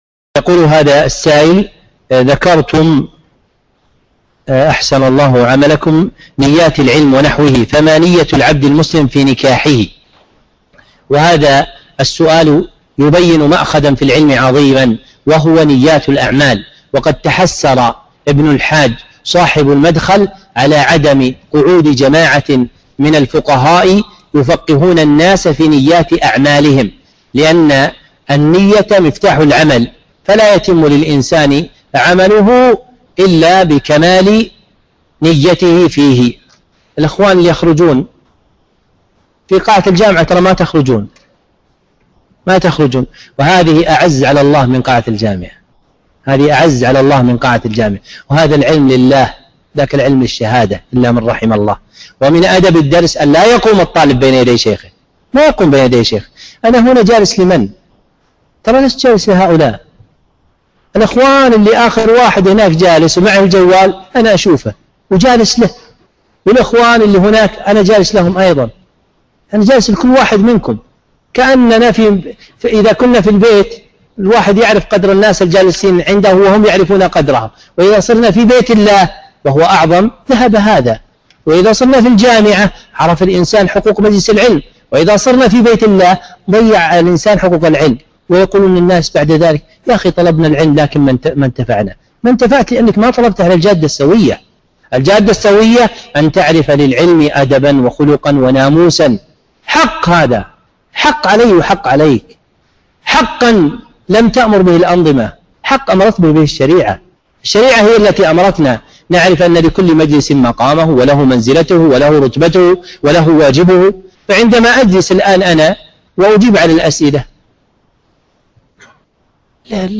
موعظة